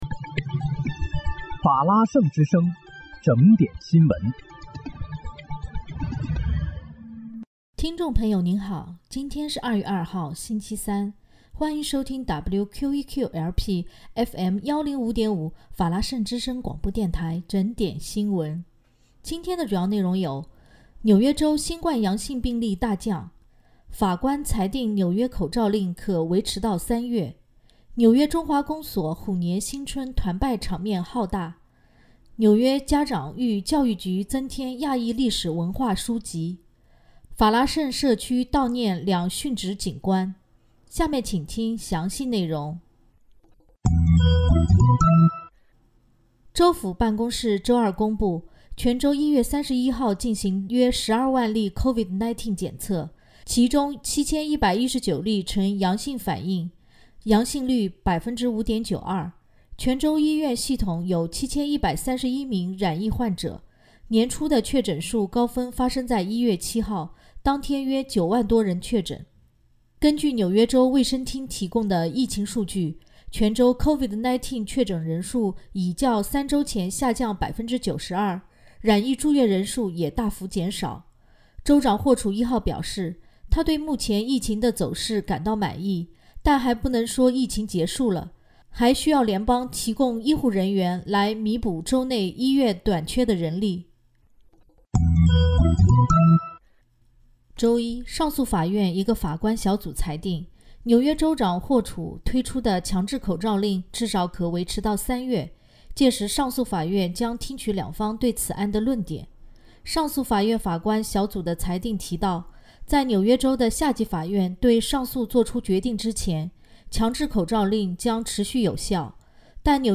2月2日（星期三）纽约整点新闻